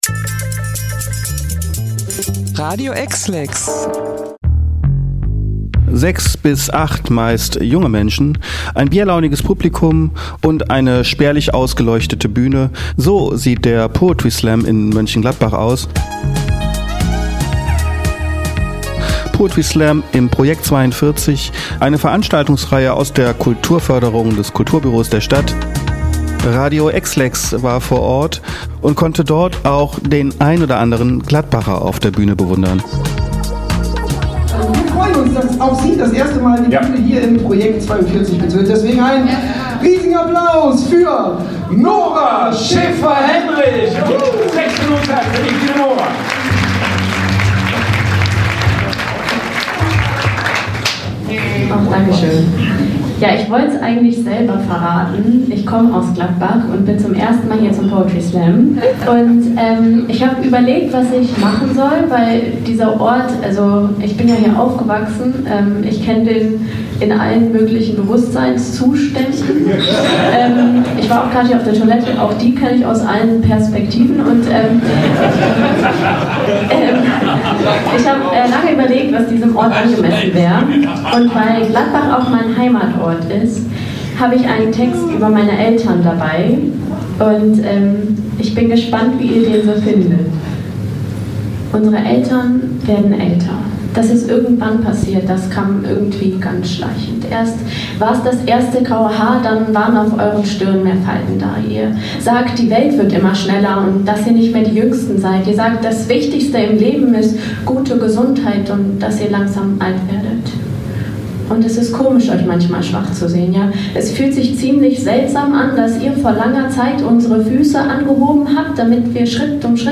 In den gedimmten Räumlichkeiten des „Projekt 42“ findet einmal im Monat der Poetry-Slam-Abend statt, der Begeisterte aus allen Winkeln der Stadt anlockt.
Zwei der Gäste kennen die Antworten!